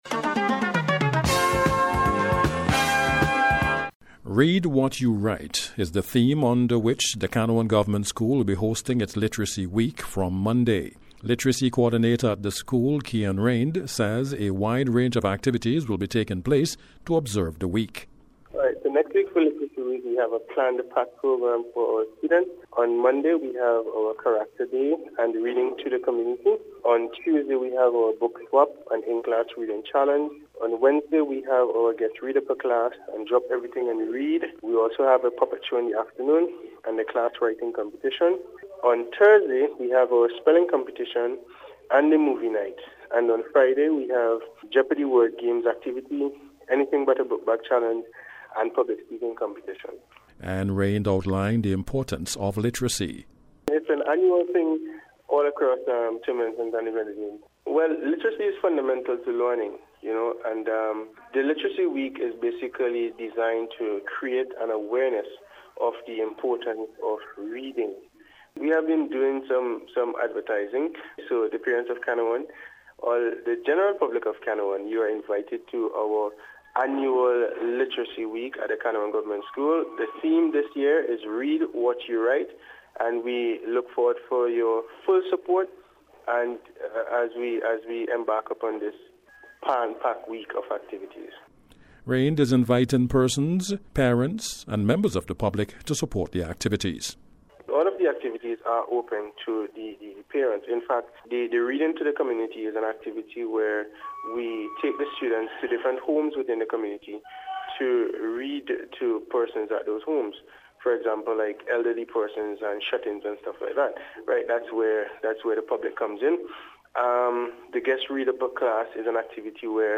NBC’s Special Report – Friday February 2nd 2024